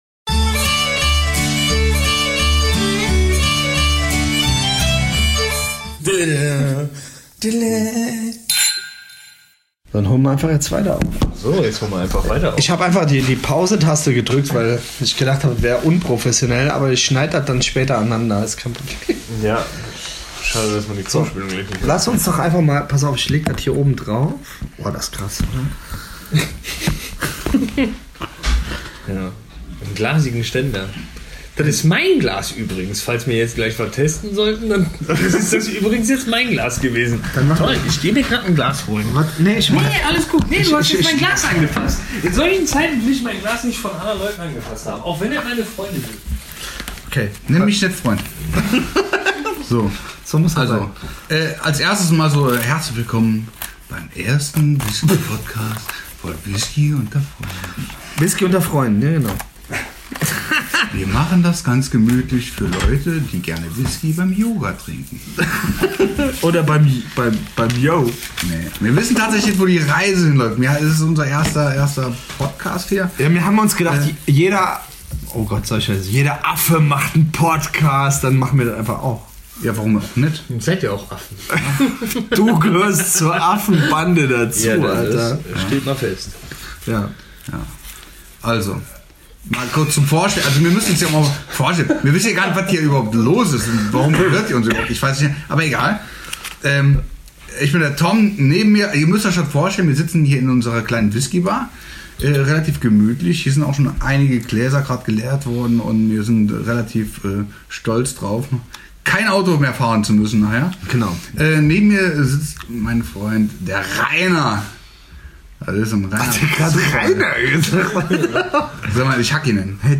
In der ersten Folge versuchen die drei Jungs krampfhaft diesen Whisky Podcast zu planen.